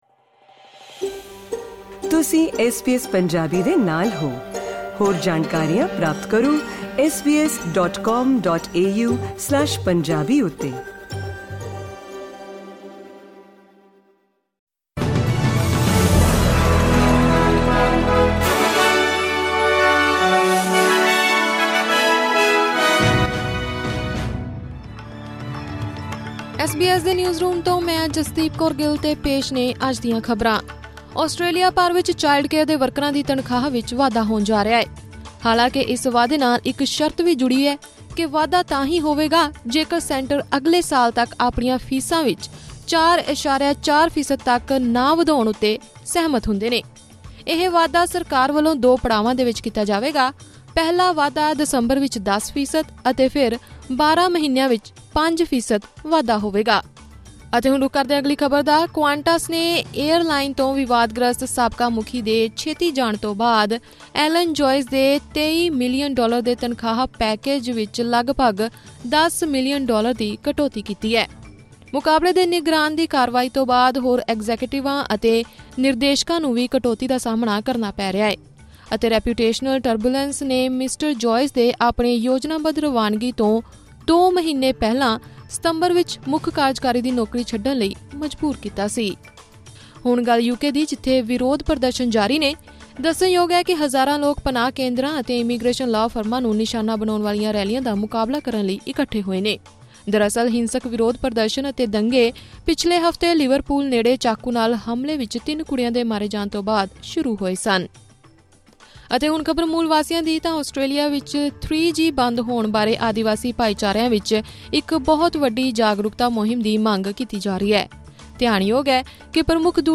ਐਸ ਬੀ ਐਸ ਪੰਜਾਬੀ ਤੋਂ ਆਸਟ੍ਰੇਲੀਆ ਦੀਆਂ ਮੁੱਖ ਖ਼ਬਰਾਂ: 8 ਅਗਸਤ 2024